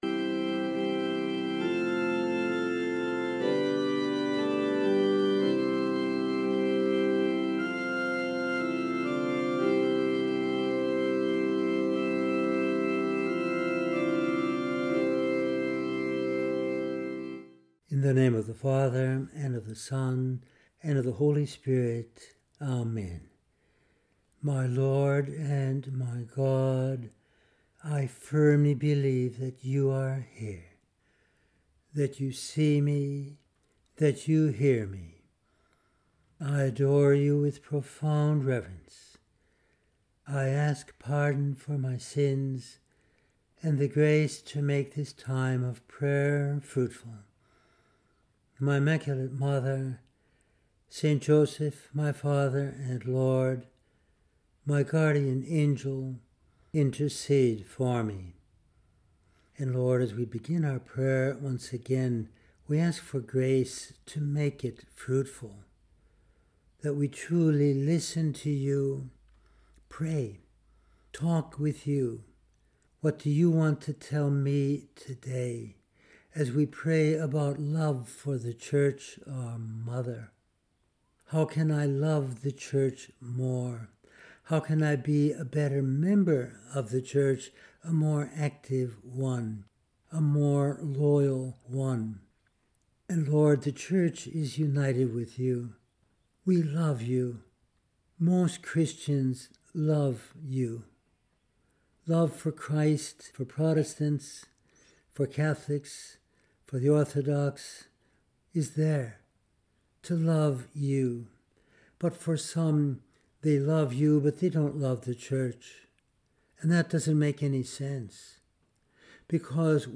In this meditation we use texts of Scripture, Fathers of the Church, Popes and St Josemaria Escriva to consider how: